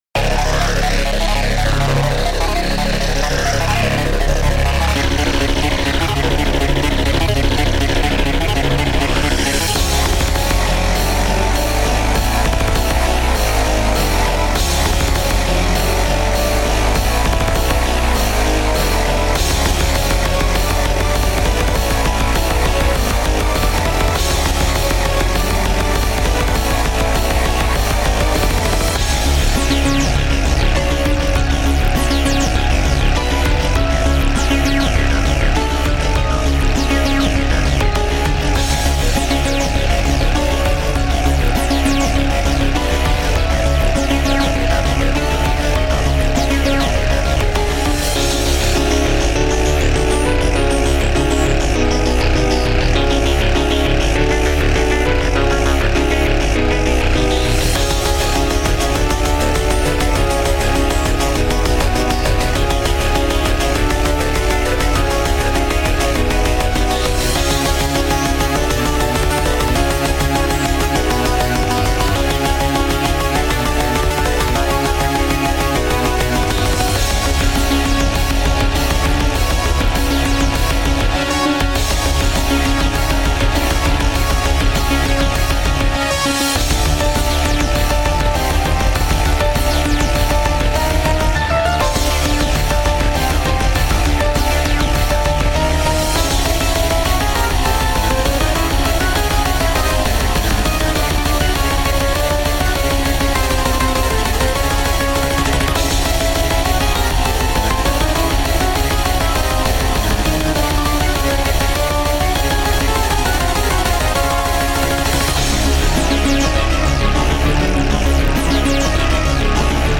BPM: 100-110